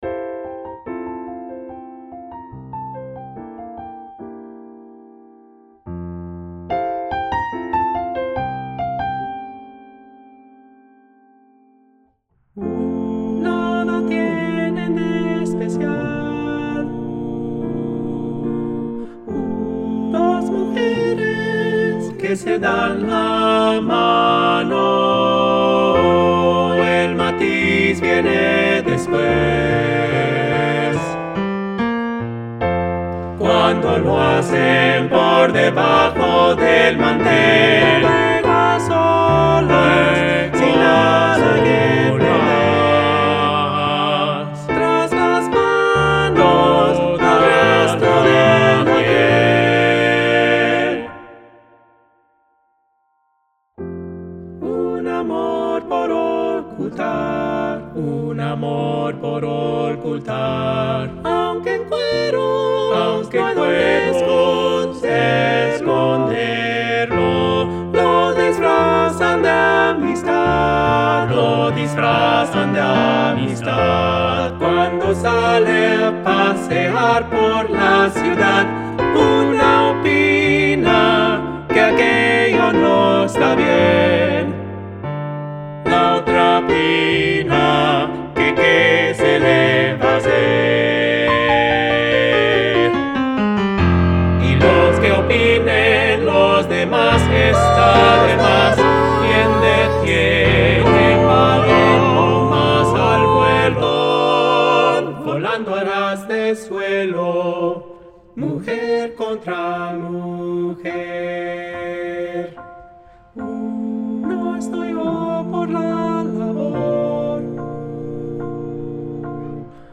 SATB + Piano 4’10”
SATB, Piano